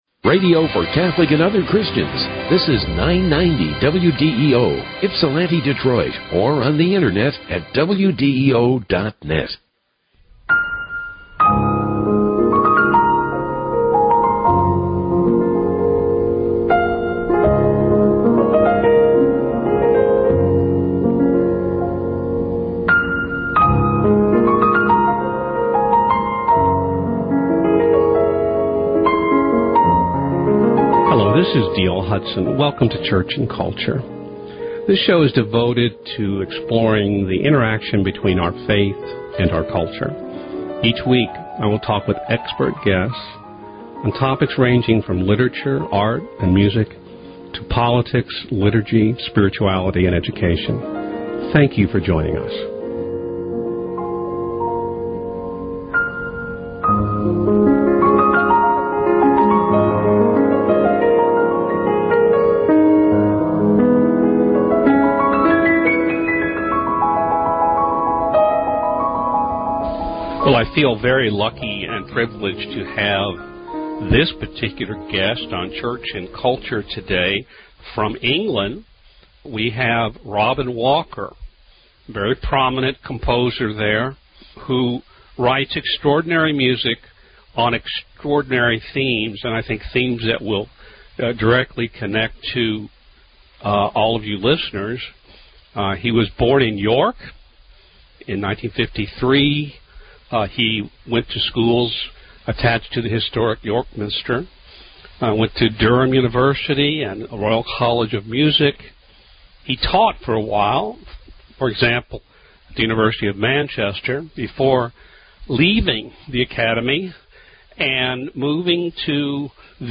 plays and discusses his life and music